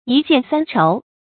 一献三酬 yī xiàn sān chóu
一献三酬发音
成语注音 ㄧ ㄒㄧㄢˋ ㄙㄢ ㄔㄡˊ